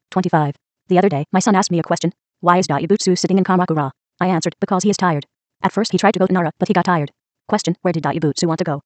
◇音声は日本語、英語ともに高音質のスピーチエンジンを組み込んだ音声ソフトを使って編集してあります。
音声−高速